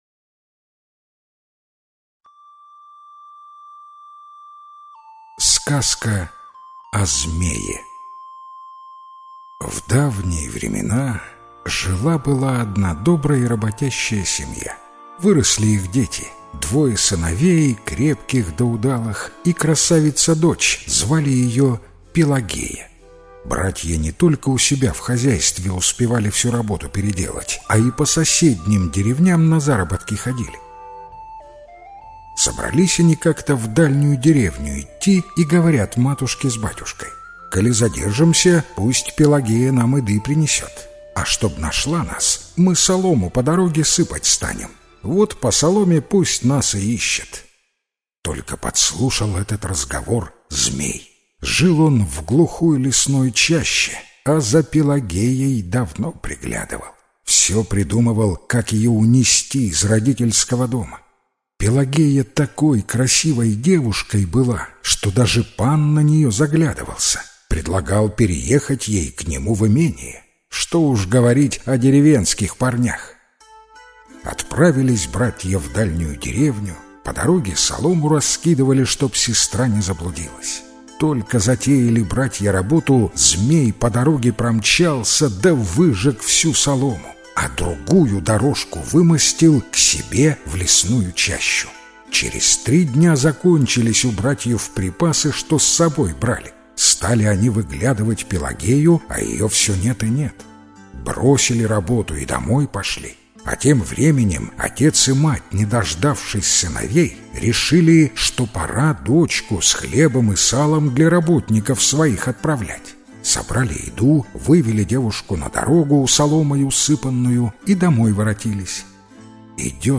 Сказка о змее - белорусская аудиосказка - слушать онлайн